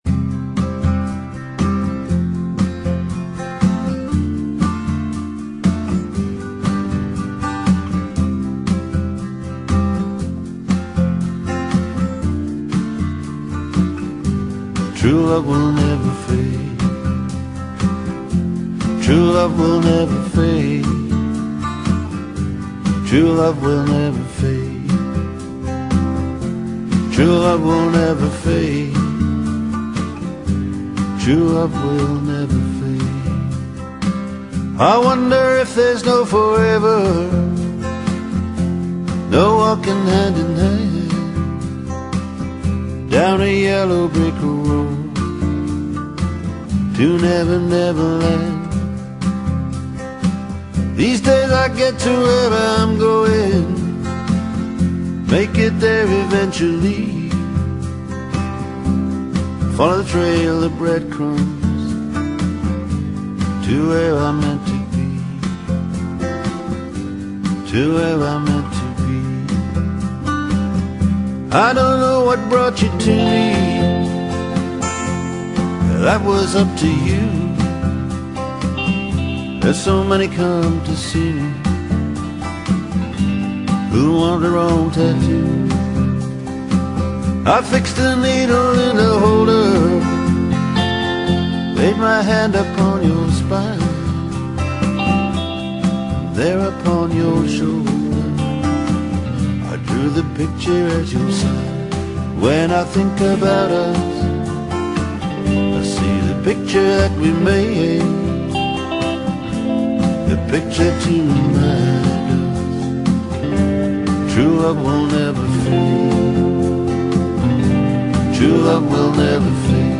Country Para Ouvir: Clik na Musica.